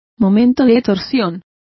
Complete with pronunciation of the translation of torques.